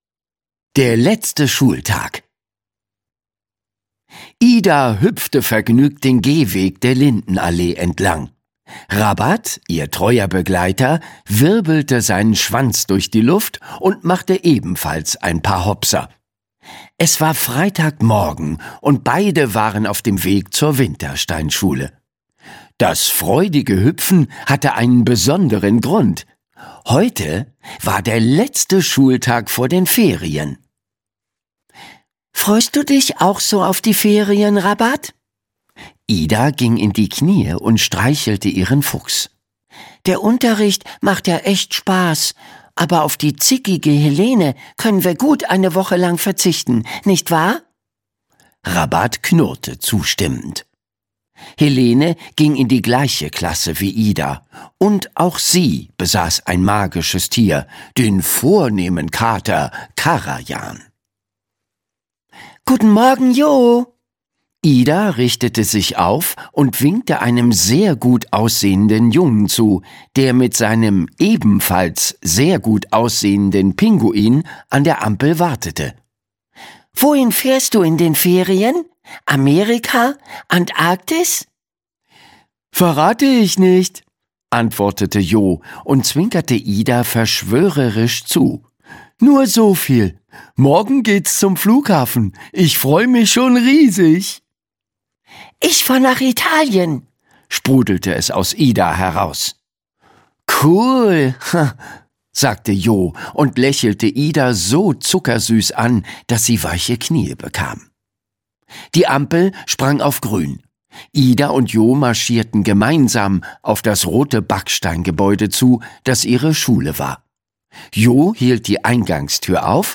Hörbuch Die Schule der magischen Tiere - Endlich Ferien 1: Rabbat und Ida, Margit Auer.